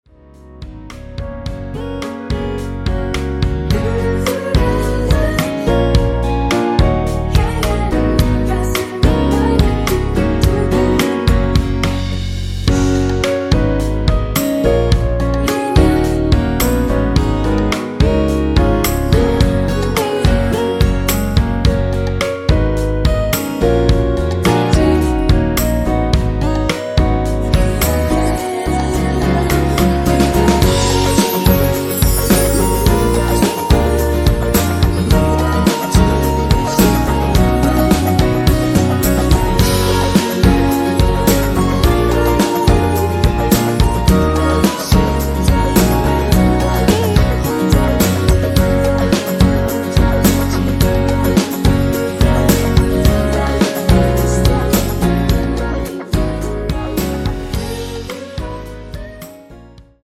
원키에서(-1)내린 멜로디와 코러스 포함된 MR입니다.(미리듣기 확인)
Gb
앞부분30초, 뒷부분30초씩 편집해서 올려 드리고 있습니다.
중간에 음이 끈어지고 다시 나오는 이유는
(멜로디 MR)은 가이드 멜로디가 포함된 MR 입니다.